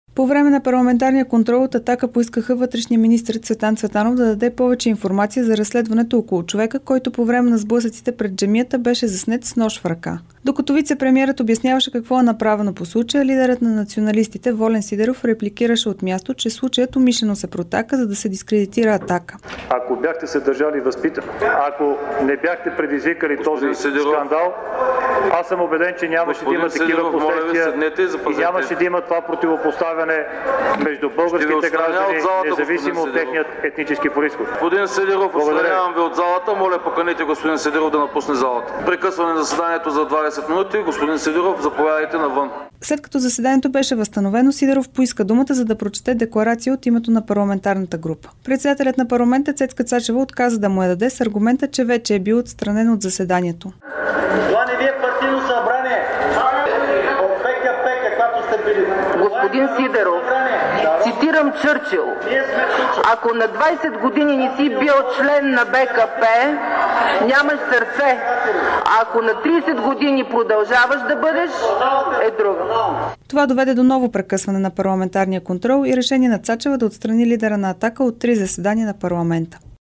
Репортаж